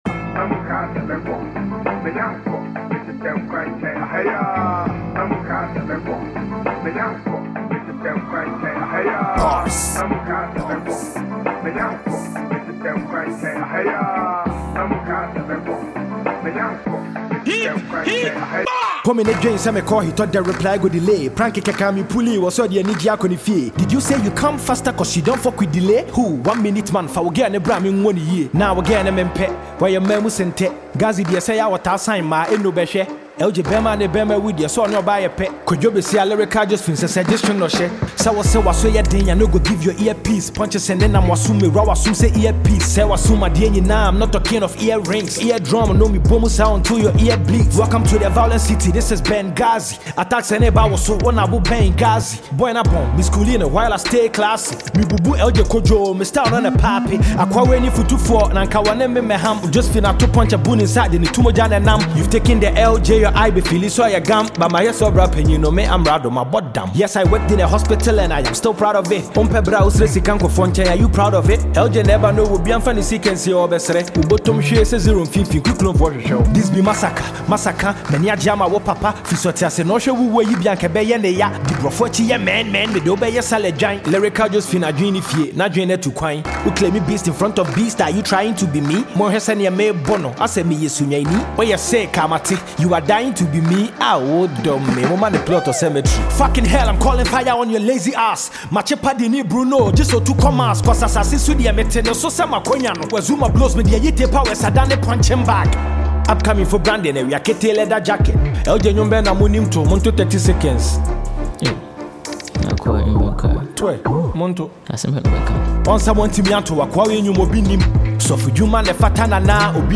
Ghana Music Music
diss song